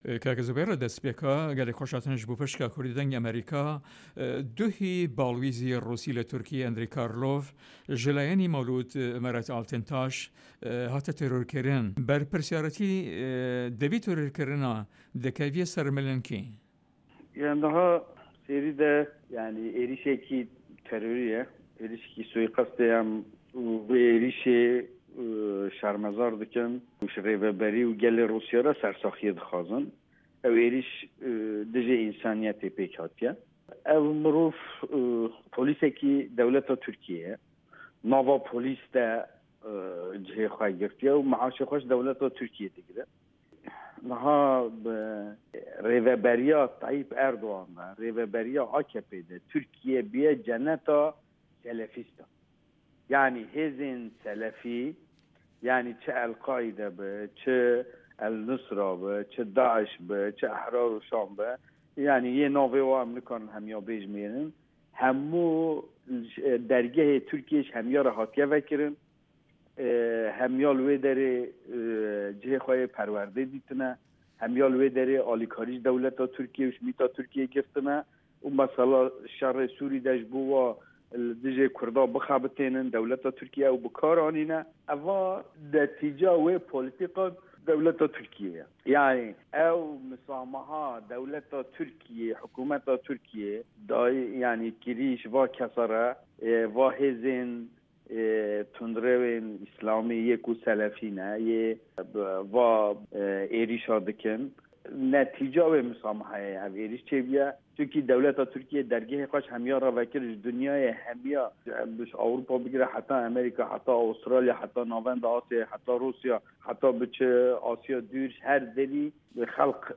Interview with Zubeyr Aydar